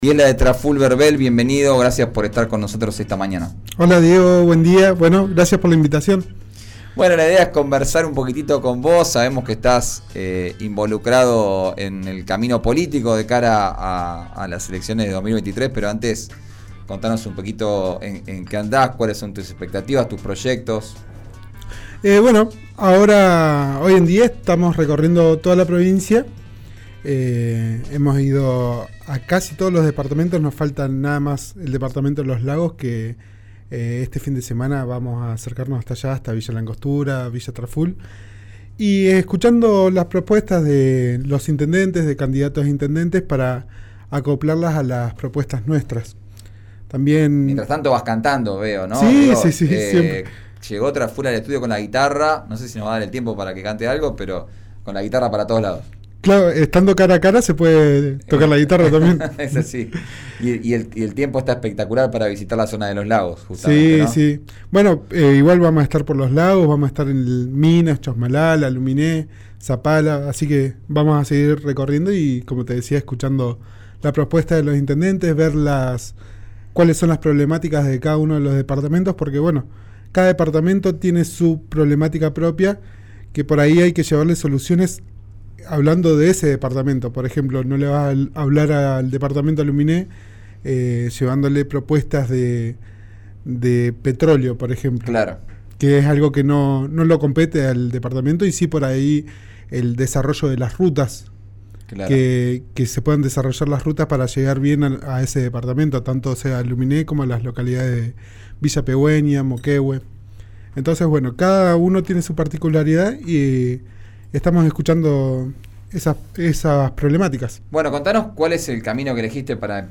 El músico explicó en diálogo con «Arranquemos», por RÍO NEGRO RADIO, que será la primera vez que dispute un cargo electivo y que la propuesta le llegó del partido UNE, el espacio que conduce Mariano Mansilla.